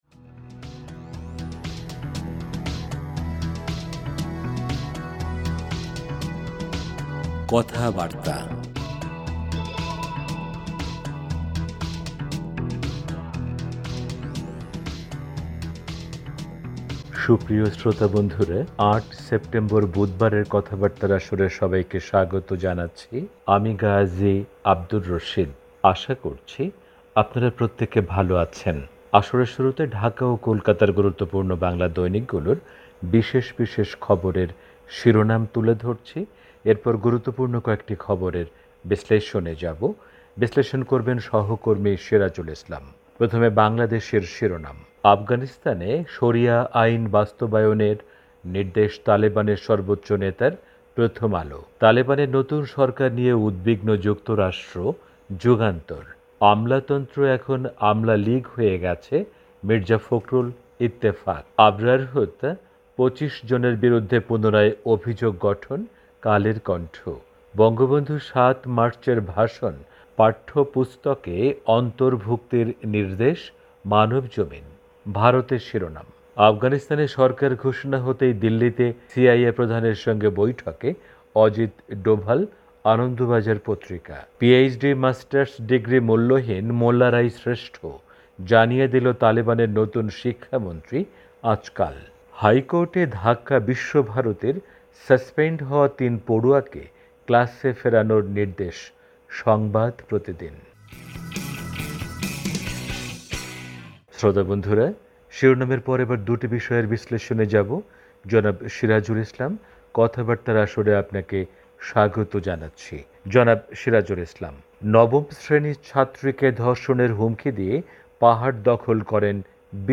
রেডিও অনুষ্ঠানমালা